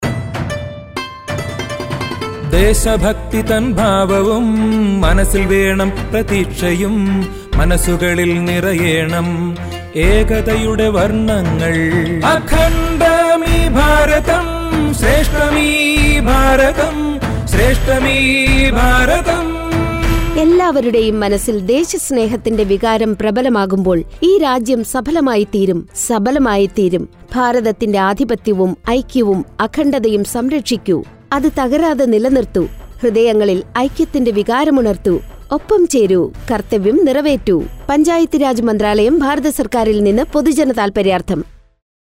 126 Fundamental Duty 3rd Fundamental Duty Protect sovereignty & integrity of India Radio Jingle Malayamlam